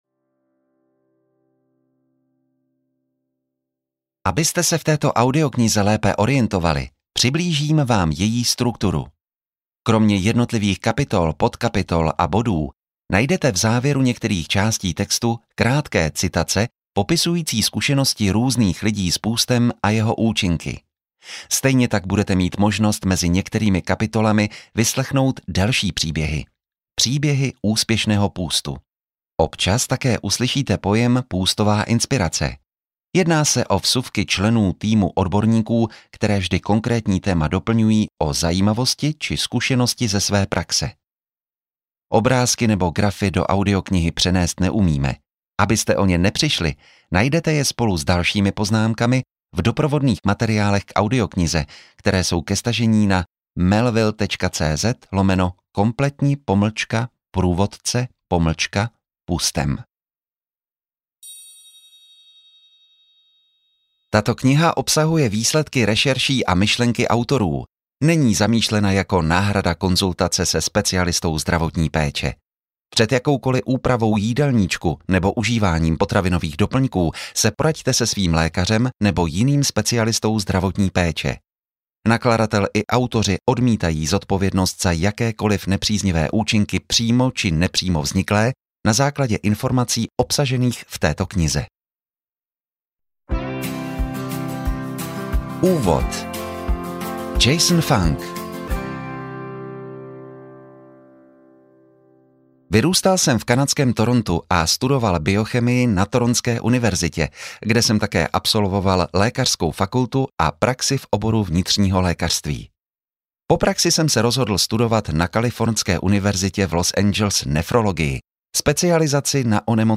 Kompletní průvodce půstem audiokniha
Ukázka z knihy